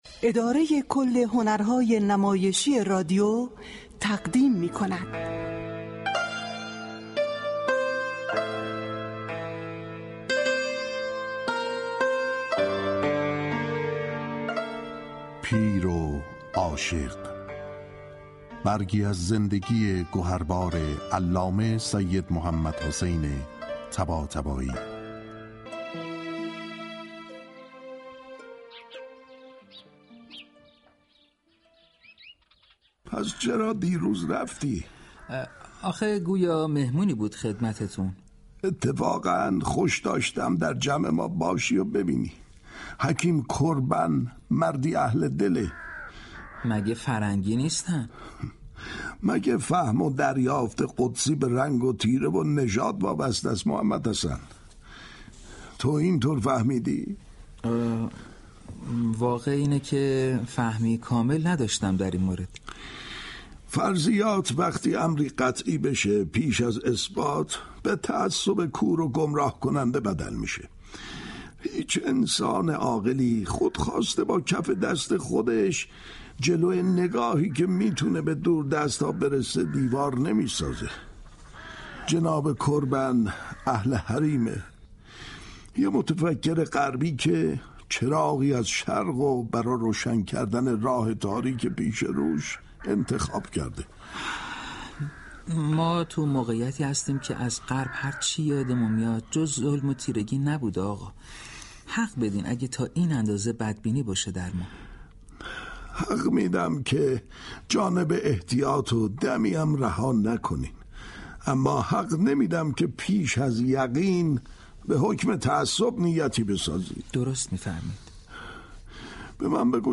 24 آبان ماه ، نمایش رادیویی